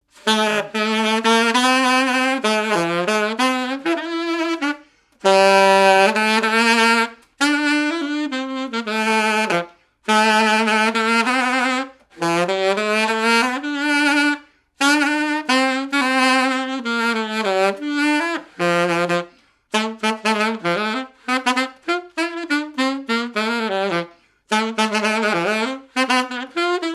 Marche de noce
Saint-Martin-Lars
activités et répertoire d'un musicien de noces et de bals
Pièce musicale inédite